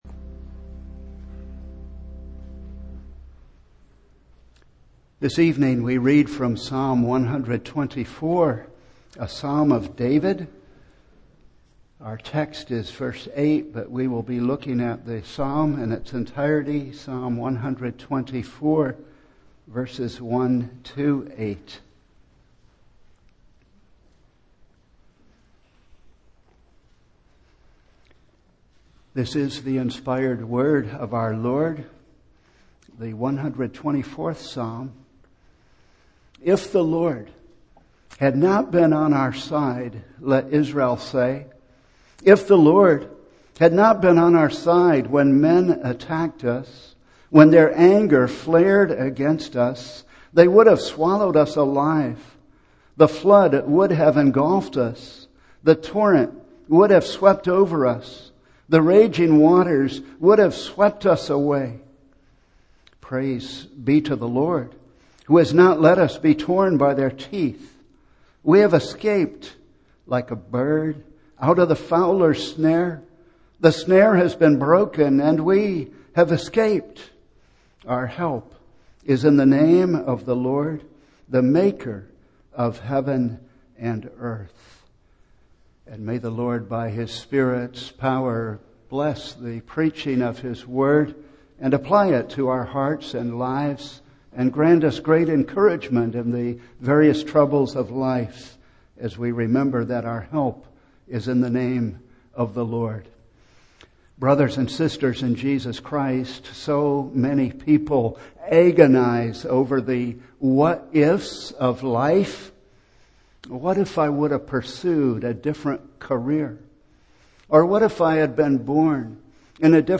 Series: Single Sermons
Service Type: Evening